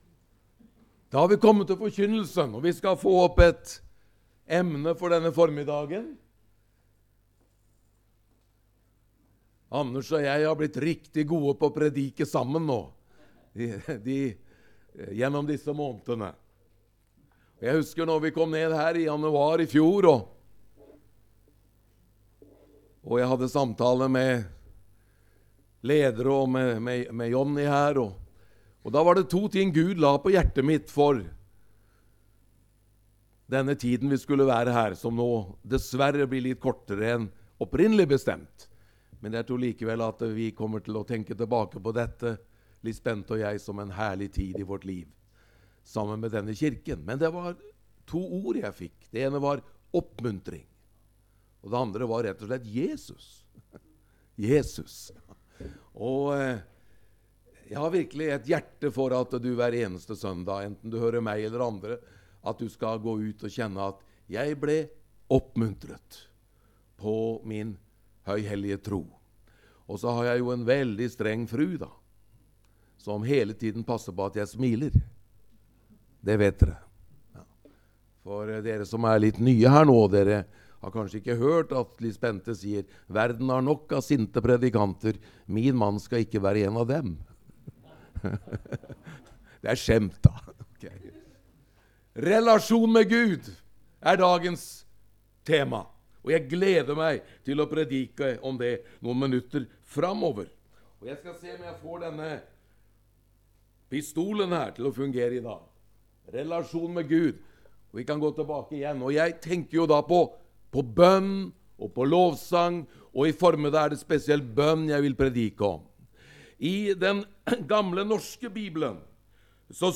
Predikan og bibelstudium – Skandinaviska Turistkyrkan Costa del Sol